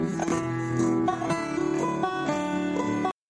I made this brief audio clip, so you can hear the muted note. Guitar and steel guitar players call this technique, 'Chicken Pickin.'